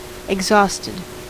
Ääntäminen
US : IPA : [ɪɡ.ˈzɔs.tɪd]